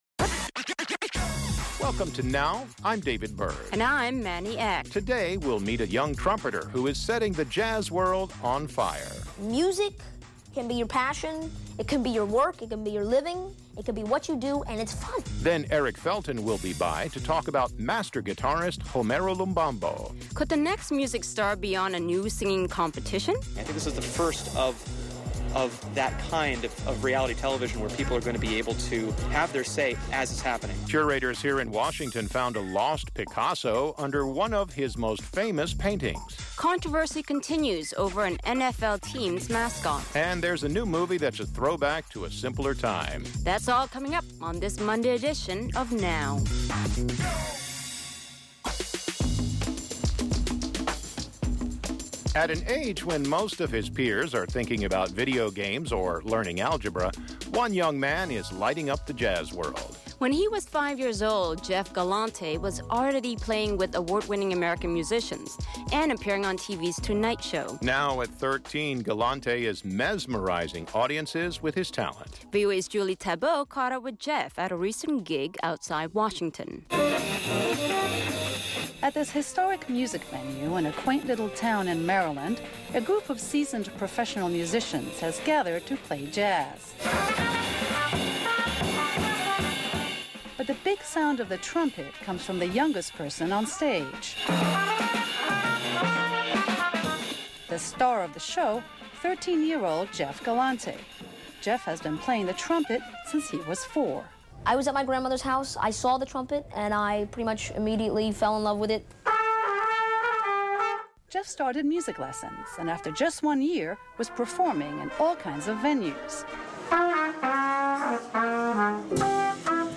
Now! is a multi-media conversation between you and program hosts